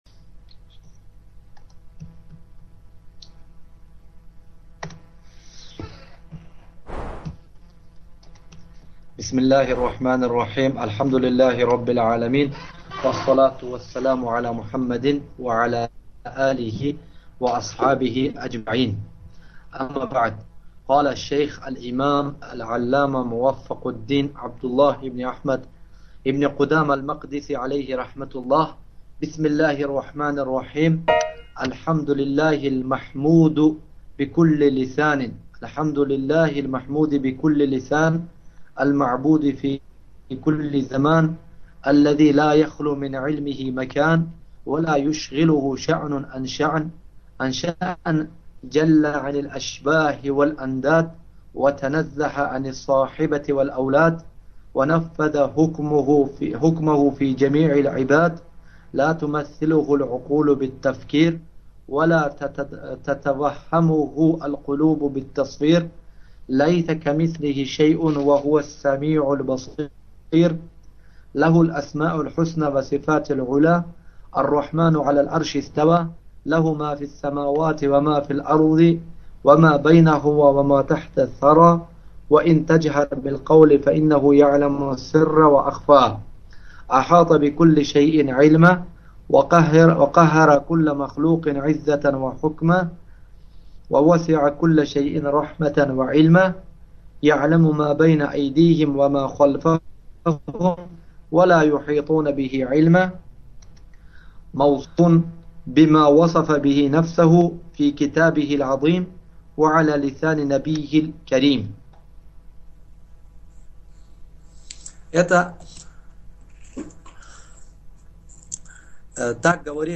1 Урок:  Знания